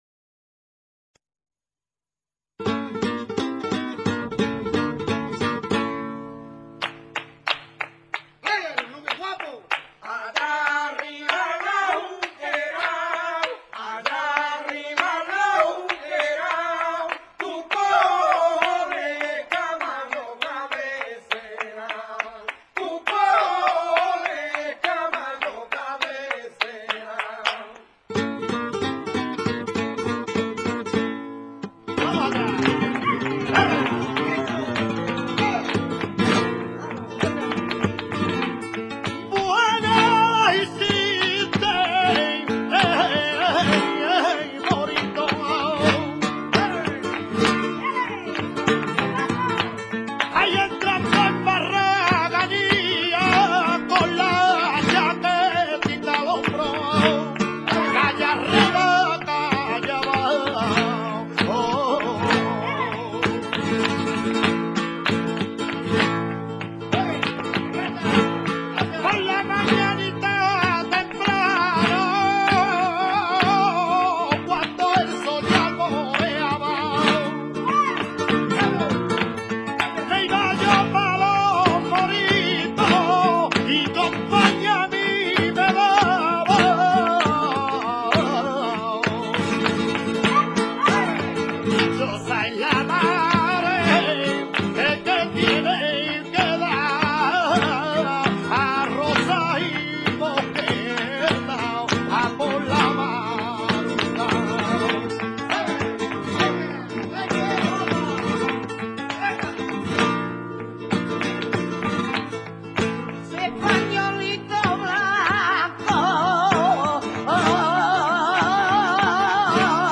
Cante con copla por lo general de cuatro versos hexas�labos y un estribillo.
alborea.mp3